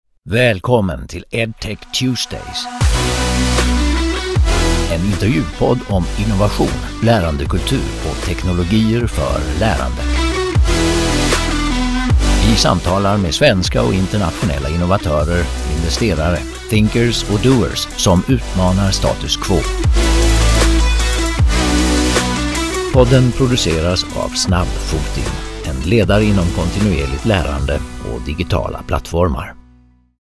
Edtech Tuesdays® är en intervjupodd om innovation, lärandekultur, organisationskultur och teknologier för lärande.